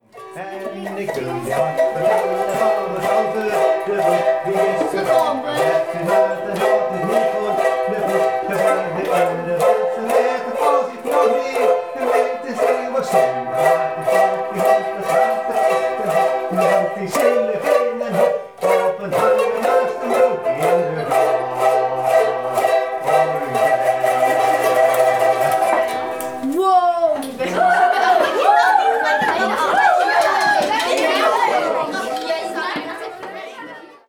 Gitaarmuziek